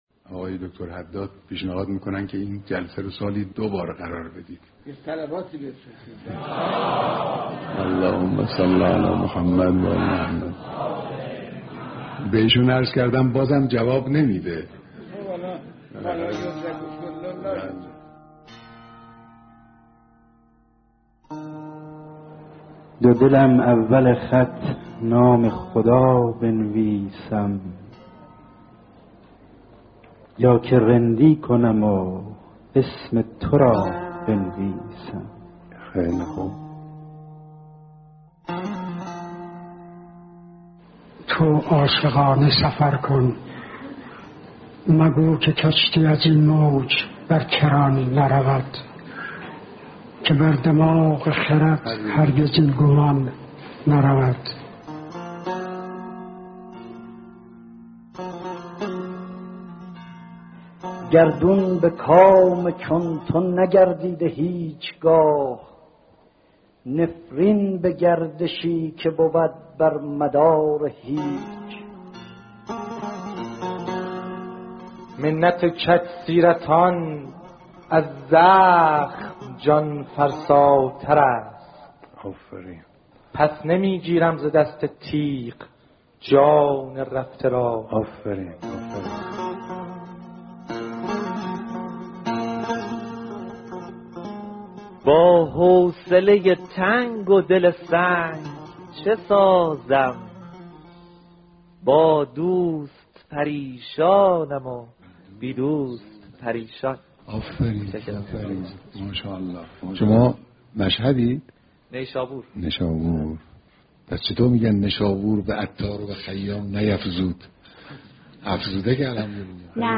شعرخوانی در محضر رهبر انقلاب